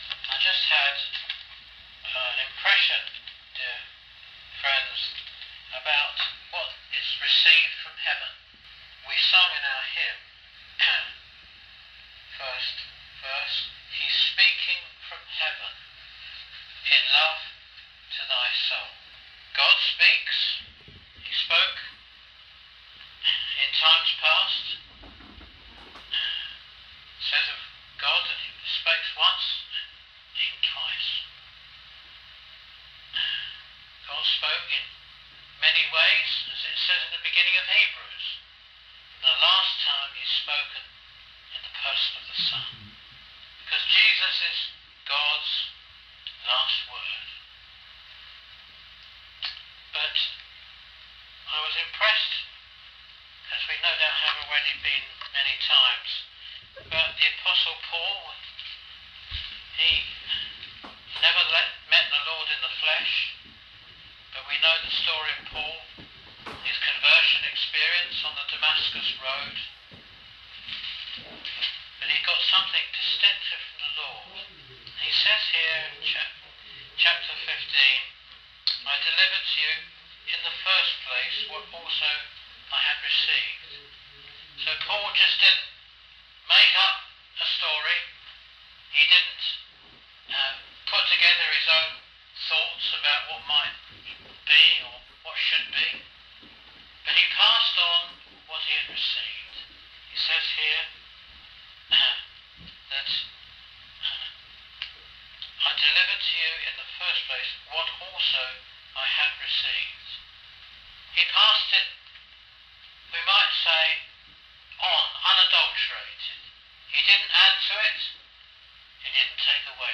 Ambler (United States)